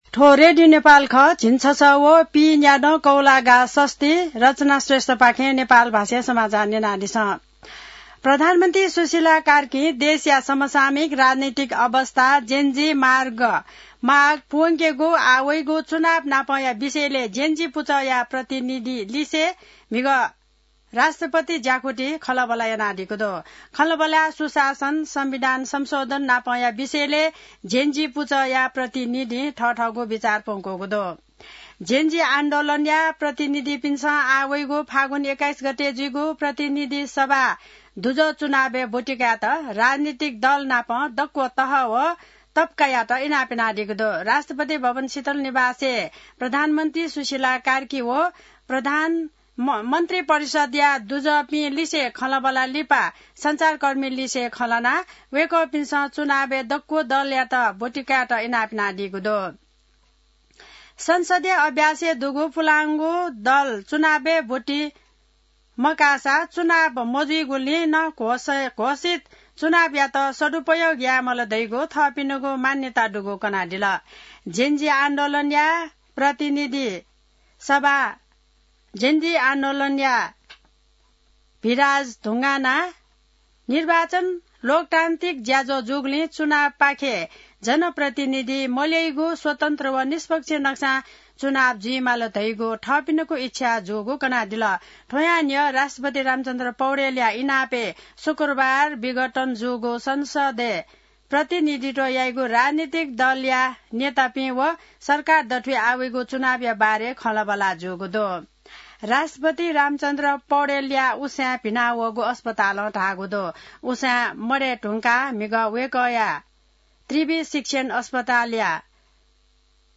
An online outlet of Nepal's national radio broadcaster
नेपाल भाषामा समाचार : २६ असोज , २०८२